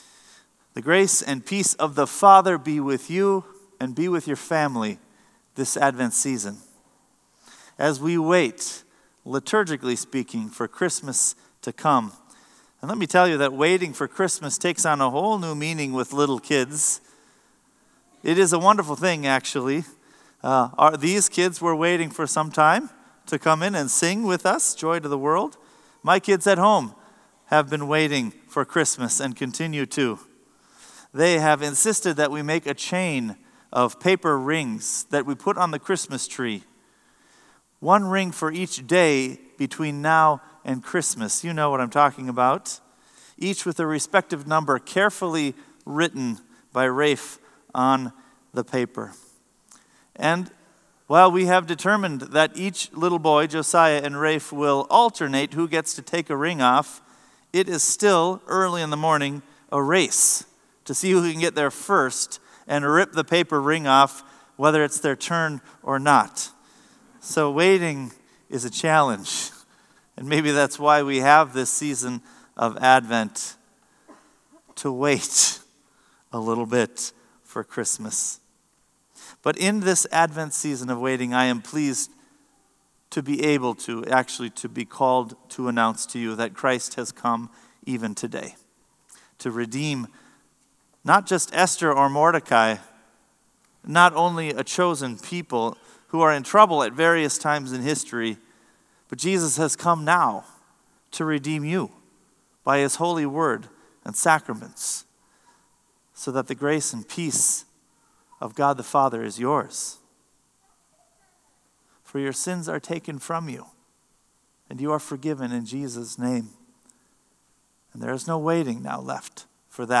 Sermon “For Such A Time As This”